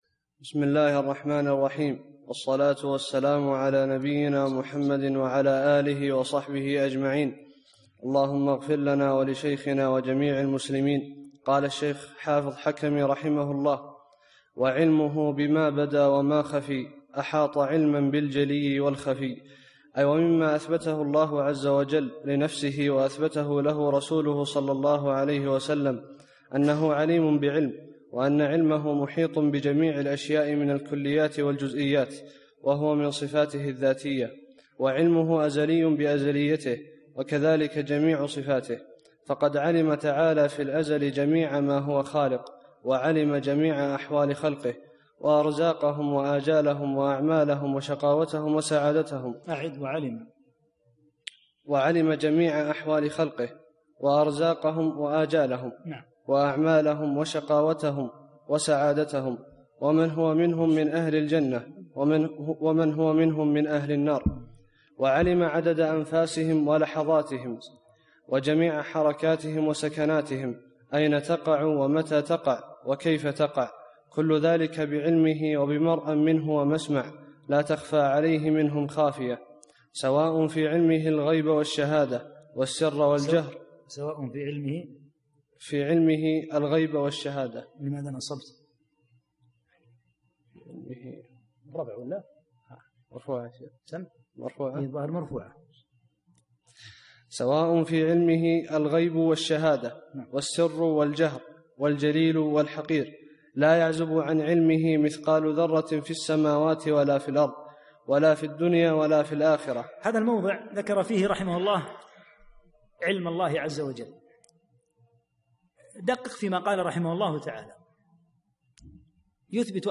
32- الدرس الثاني والثلاثون